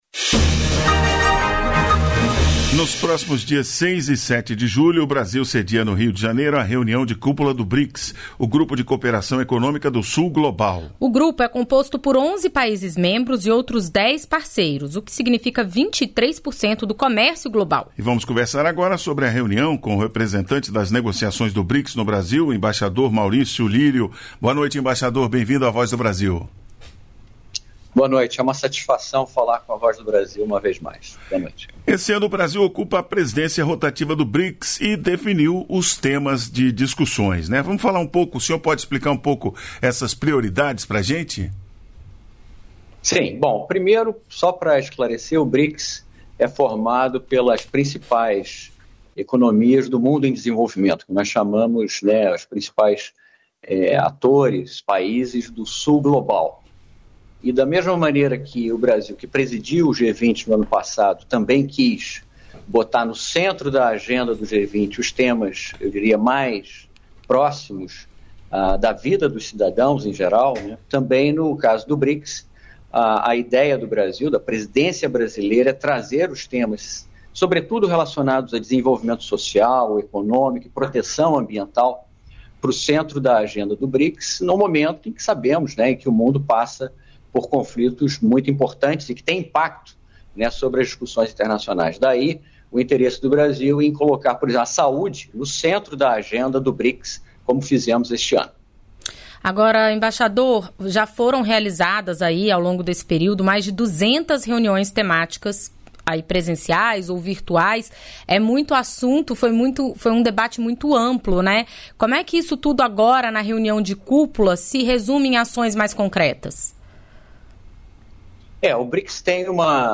Entrevista com Adrualdo Catão, Secretário Nacional de Trânsito